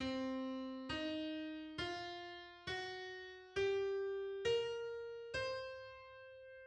English: Hexatonic blues scale on C: Blues scale as minor pentatonic plus flat-5th/sharp-4th.
Blues_scale_hexatonic_C.mid.mp3